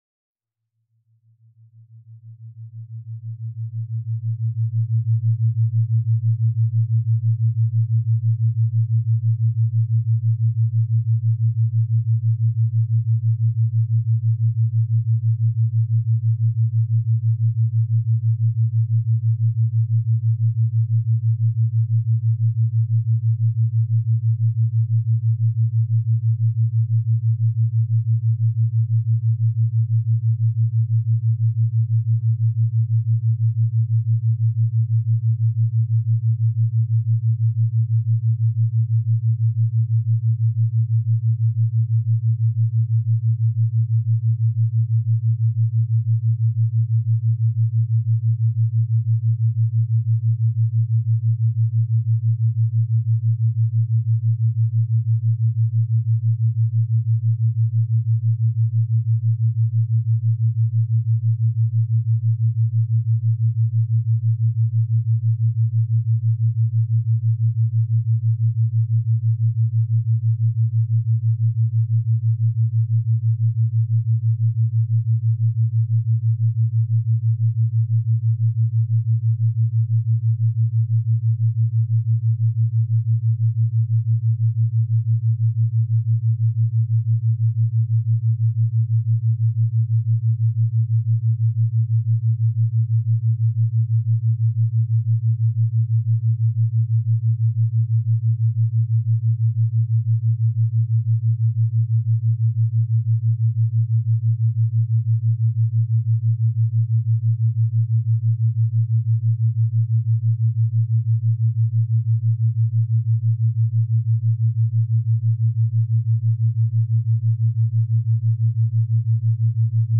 മസ്തിഷ്ക പ്രവര്‍ത്തനത്തെ (അവബോധത്തെ) ആല്ഫാ സ്റ്റേജില്‍ നില നിര്‍ത്തുവാനുള്ള ഉപകരണ സംഗീതം കേള്‍ക്കുക. (കേള്‍ക്കുമ്പോള്‍ എന്തെങ്കിലും ബുദ്ധിമുട്ട് തോന്നുന്നുവെങ്കില്‍ ഞങ്ങളെ വിളിക്കാന്‍ മറക്കരുത്.
Introscripting_Meditation.mp3